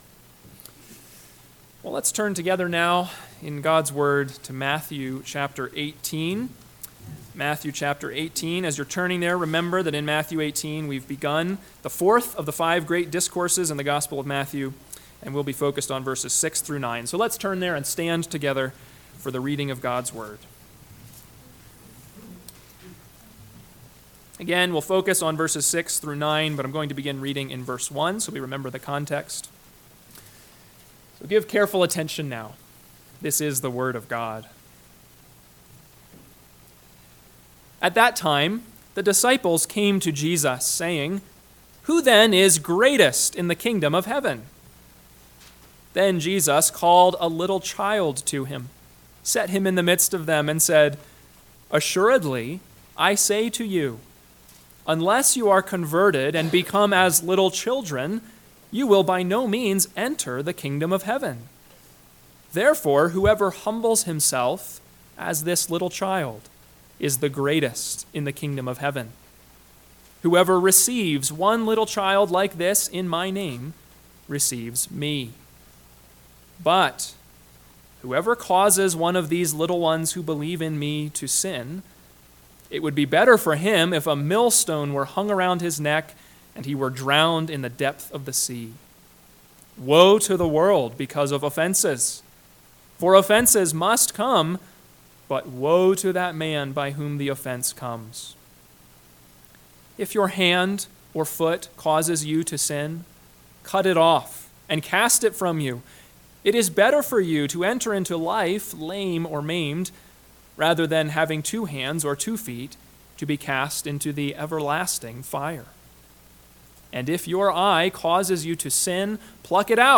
AM Sermon – 4/28/2024 – Matthew 18:6-9 – Northwoods Sermons